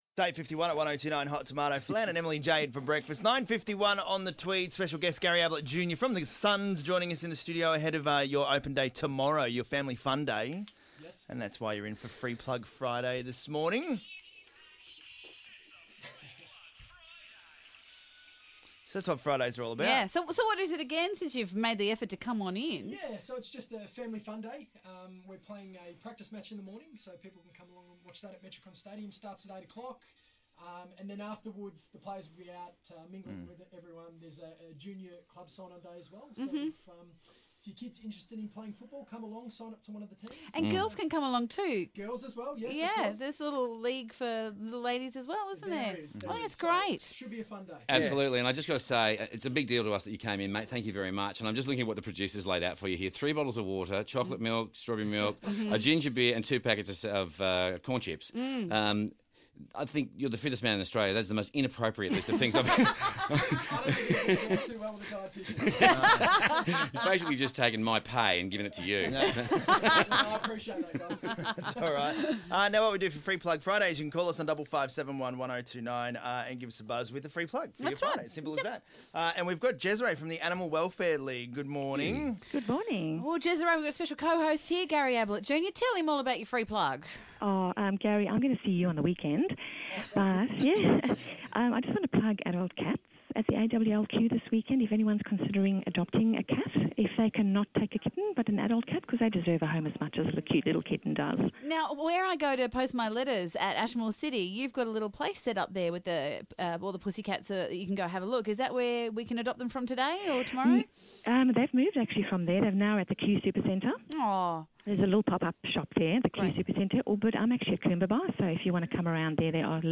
Gary Ablett Jrn spoke with Hot Tomato ahead of the Gold Coast AFL Open Day